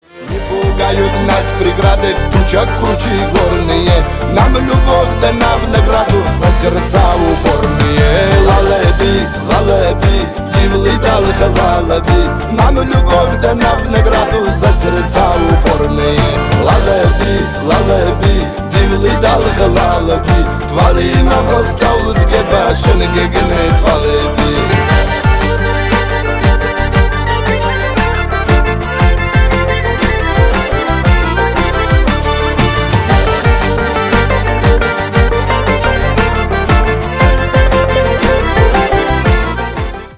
кавказские